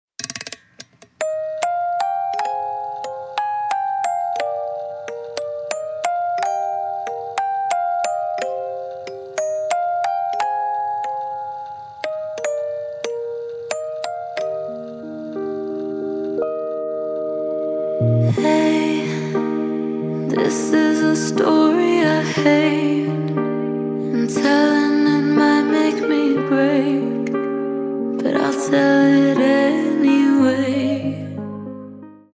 Pop & Rock